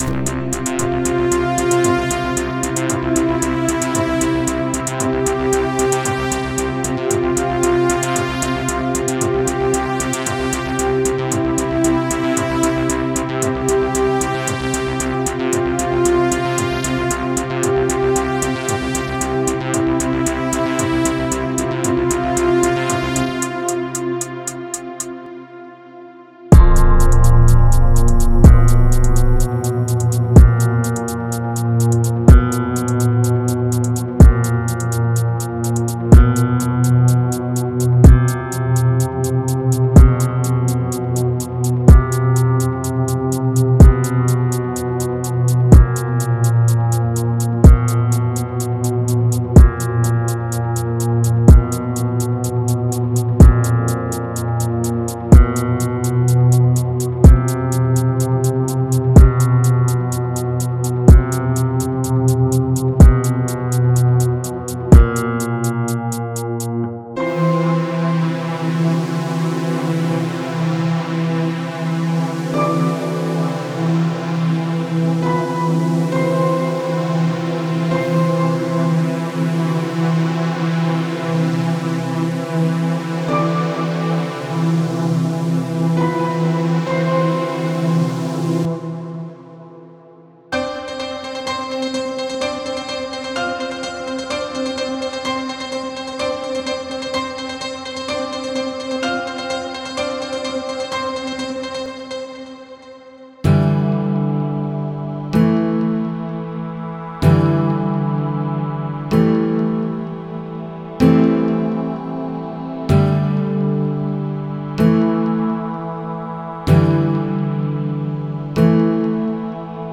5 Construction kits
Dark Pianos, Guitars, Drums etc
Demo